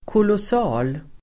Uttal: [kålås'a:l]